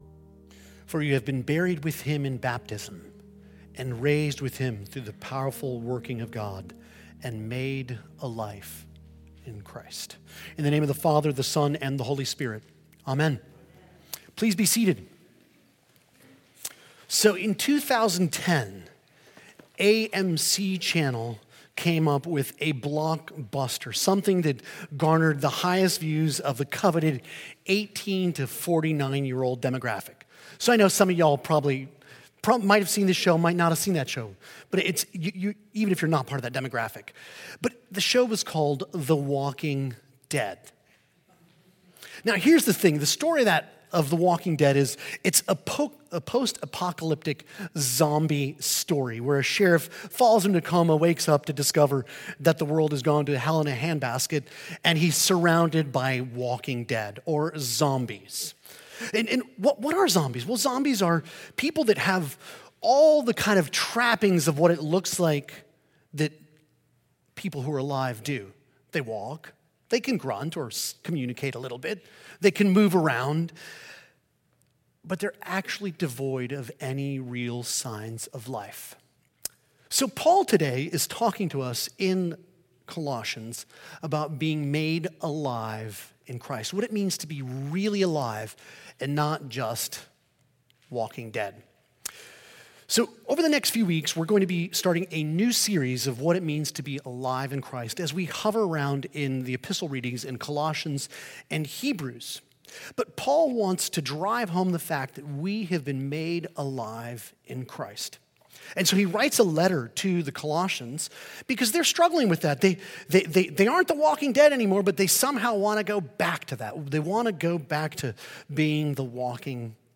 This sermon explores what it truly means to be “made alive in Christ”, contrasting spiritual vitality with the metaphor of “The Walking Dead.” Drawing from Colossians 2:6–15, this talk emphasizes that believers, once spiritually dead, have been resurrected through Christ’s power and are now called to live fully in Him. Sermon